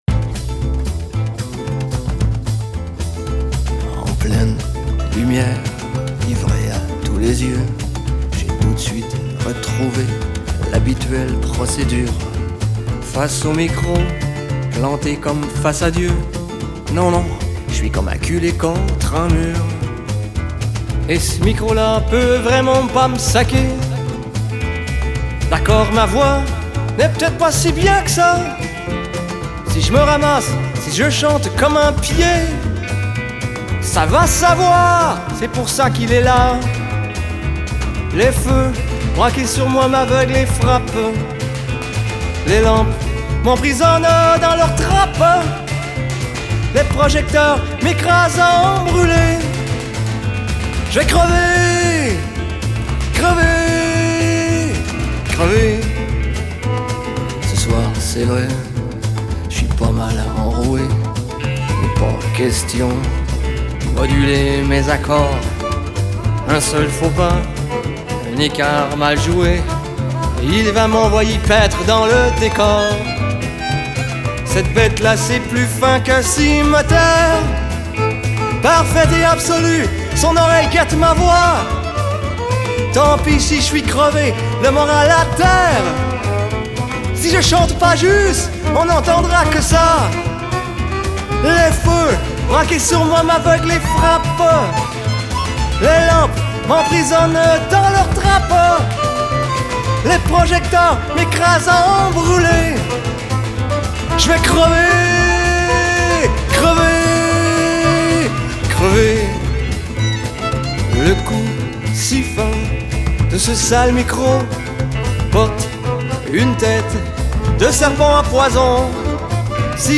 контрабас
аккордеон и скрипка
фортепиано